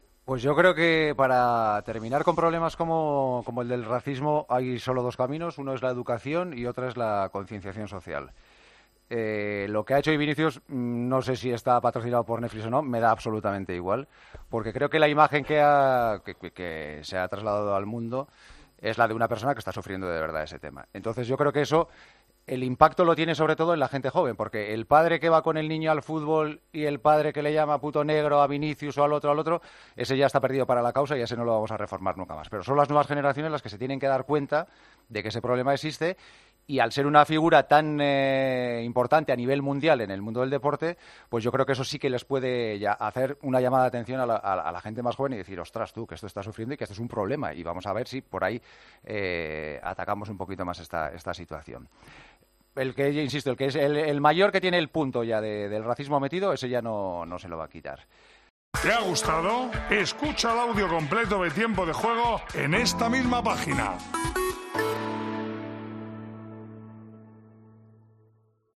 AUDIO: El presentador de El Partidazo de COPE dio un mensaje muy contundente tras ver el sufrimiento de Vinicius en rueda de prensa.